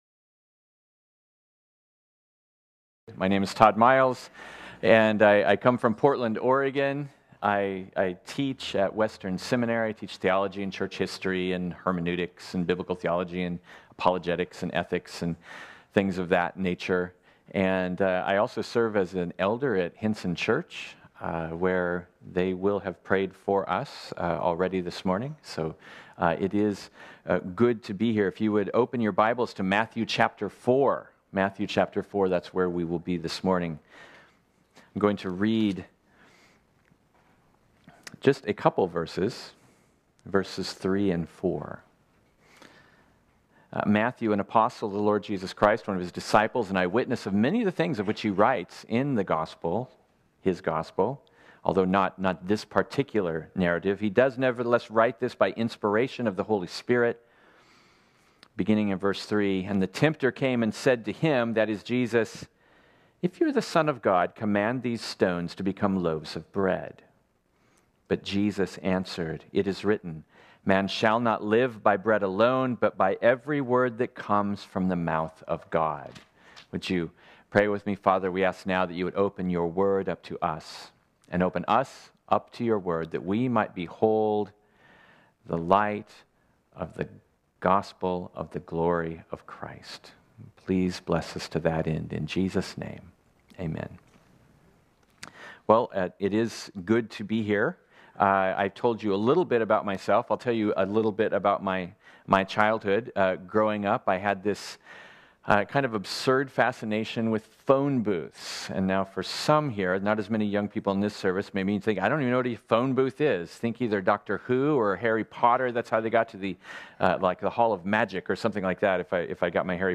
This sermon was originally preached on Sunday, June 30, 2019.